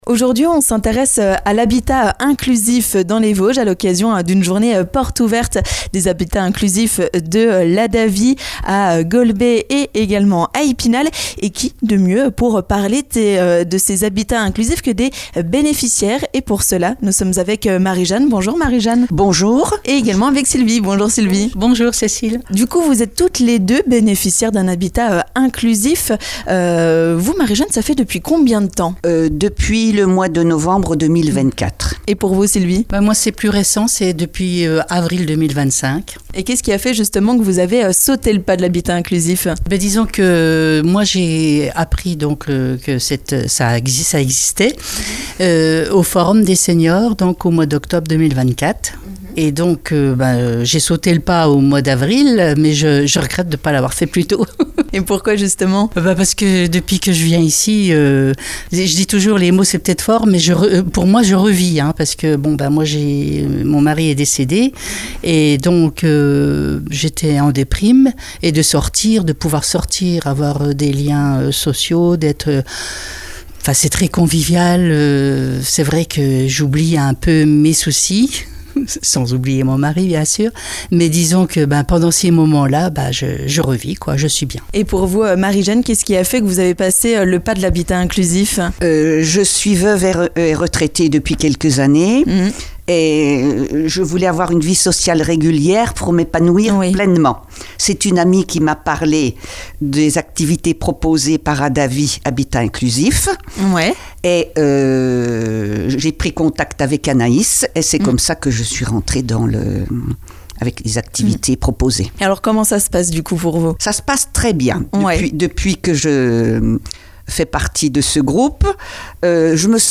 Habitat Inclusif : des bénéficiaires témoignent de leur bien-être !
%%La rédaction de Vosges FM vous propose l'ensemble de ces reportages dans les Vosges%%